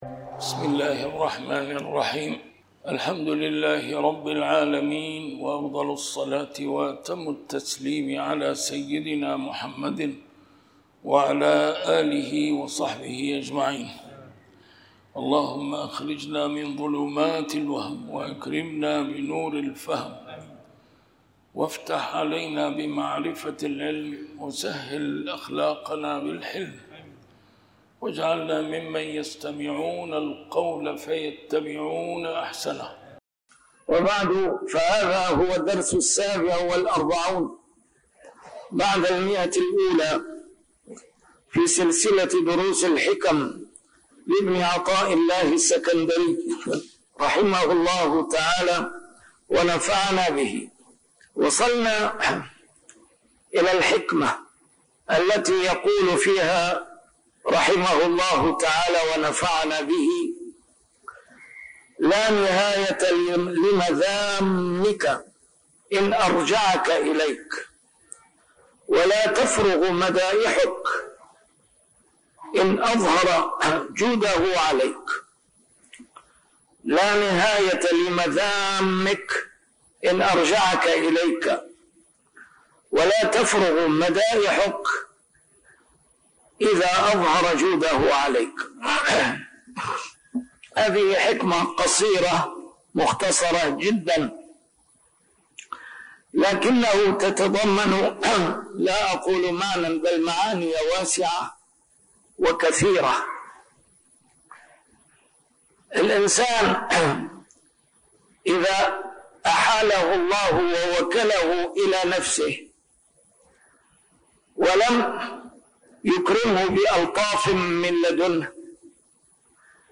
شرح الحكم العطائية - A MARTYR SCHOLAR: IMAM MUHAMMAD SAEED RAMADAN AL-BOUTI - الدروس العلمية - علم السلوك والتزكية - الدرس رقم 147 شرح الحكمة 124